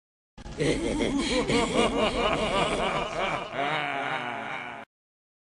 دانلود آهنگ خنده ترسناک 3 از افکت صوتی انسان و موجودات زنده
دانلود صدای خنده ترسناک 3 از ساعد نیوز با لینک مستقیم و کیفیت بالا
جلوه های صوتی